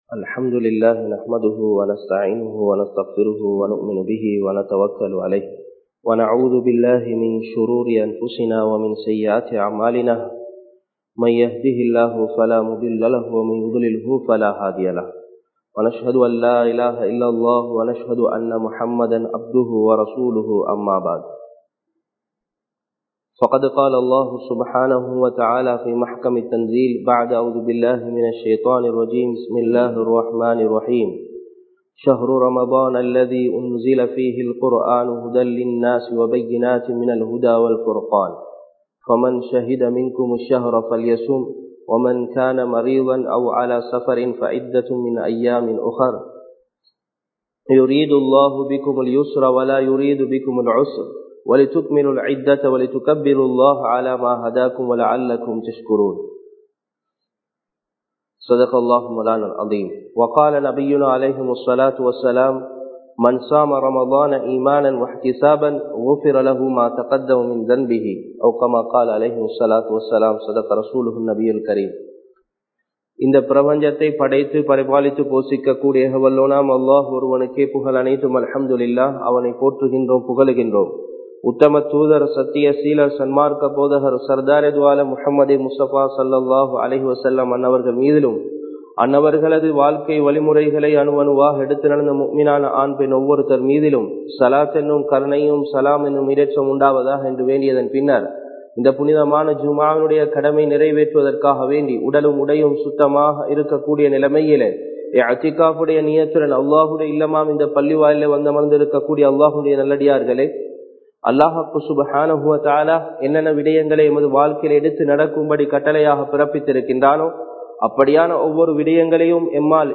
ரமழானும் அமல்களும் | Audio Bayans | All Ceylon Muslim Youth Community | Addalaichenai
Sabeelur Rashad Jumua Masjidh